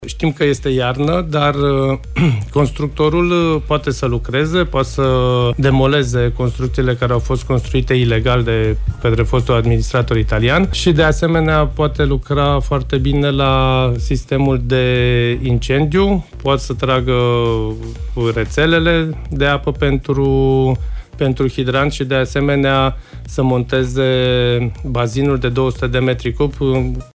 Şcoala şi  biserica din Cetate, acum ruine, vor fi reconstruite. De asemenea, turnurile dărâmate parţial vor fi refăcute, iar în incinta cetăţii vor fi amenajate 15 spaţii de expoziţii şi cinci ateliere, mai spune primarul: